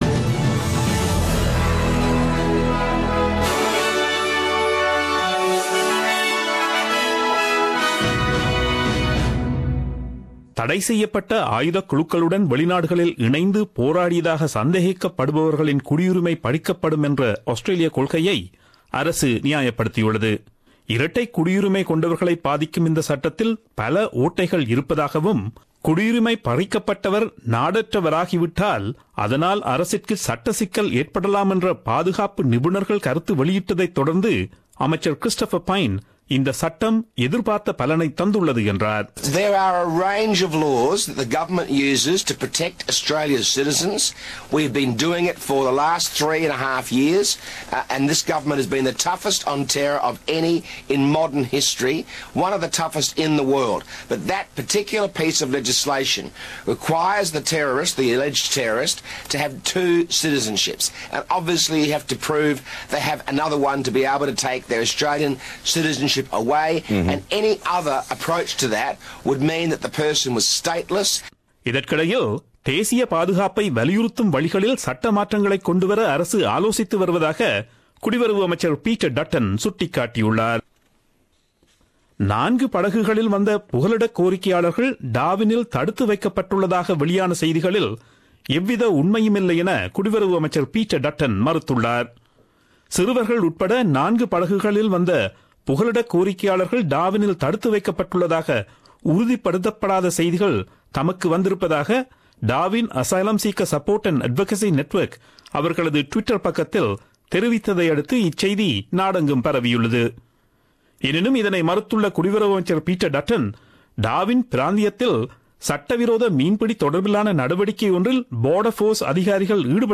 Australian news bulletin aired on Friday 03 Mar 2017 at 8pm.